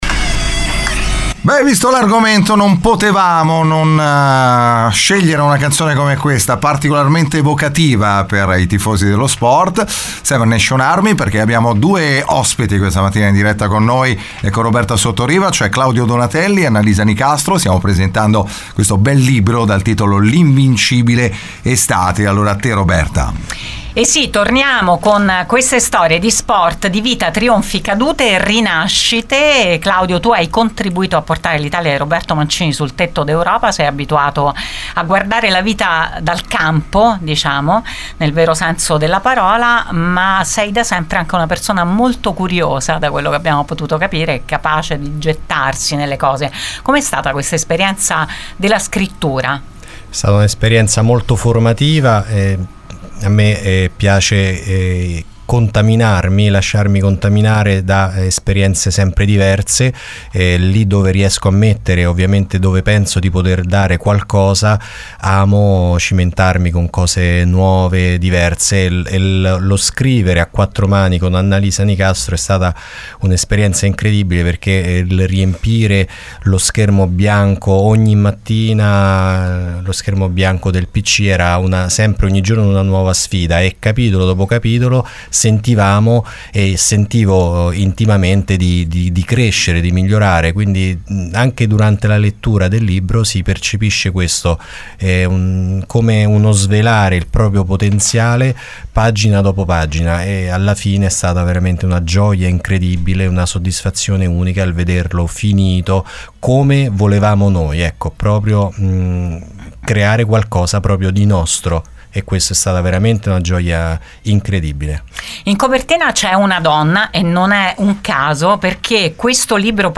E mentre il volume (che riporta in copertina volutamente l’immagine di una donna che corre), scala le classifiche e i due autori passano di incontro in incontro girando tutta Italia, noi ne abbiamo parlato con loro questa mattina su Radio Immagine.